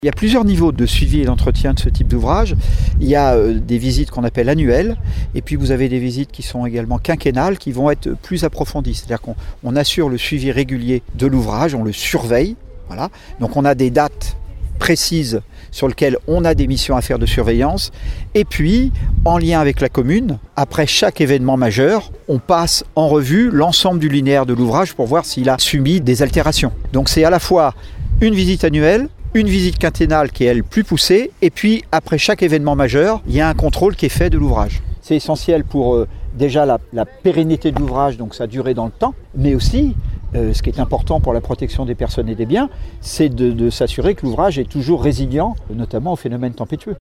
L’enjeu pour les années à venir sera de pérenniser l’ouvrage, une fois livré en fin d’année à la Communauté d’Agglomération Rochefort Océan. La CARO qui sera chargée d’en assurer l’entretien, comme l’explique Alain Burnet, vice-président en charge de la gestion des milieux aquatiques et de la prévention des inondations :